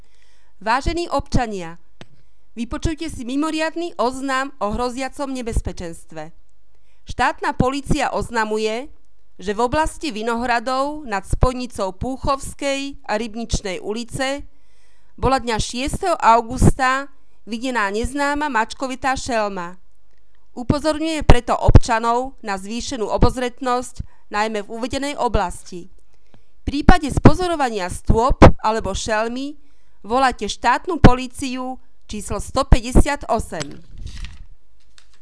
Hlásenie rozhlasu
• Mimoriadne hlásenie o hroziacom nebezpečenstve - mačkovitej šelme